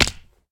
fallsmall.ogg